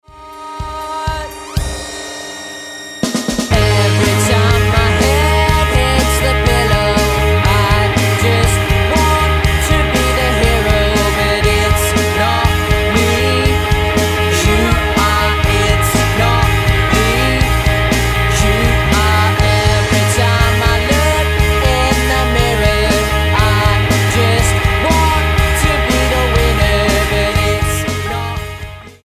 indie rock band
Style: Rock